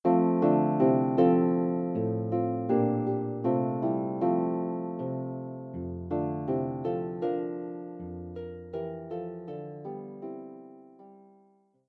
arranged for solo lever or pedal harp